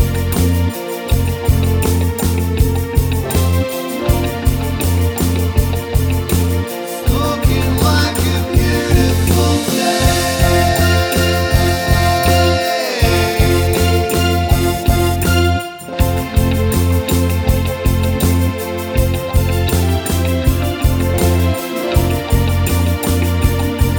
no Backing Vocals Indie / Alternative 3:49 Buy £1.50